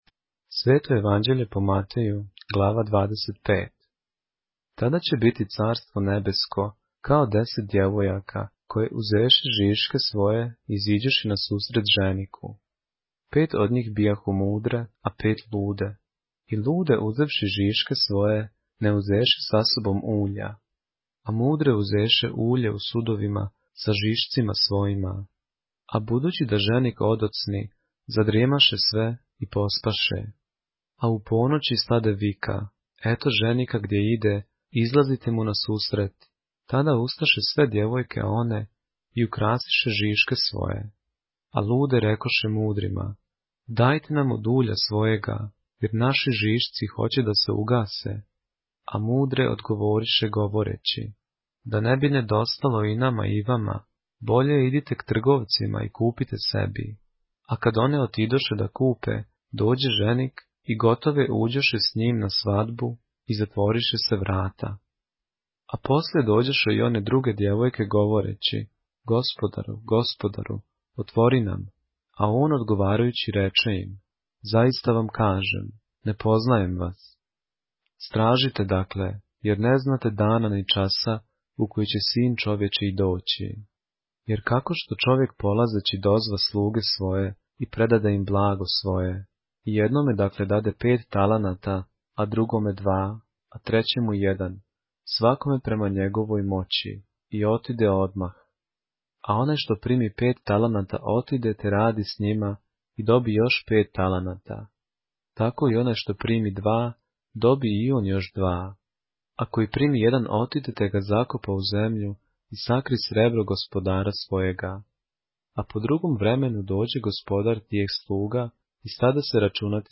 поглавље српске Библије - са аудио нарације - Matthew, chapter 25 of the Holy Bible in the Serbian language